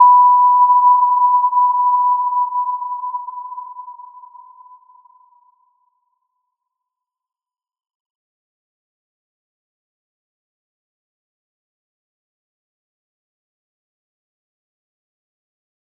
Little-Pluck-B5-p.wav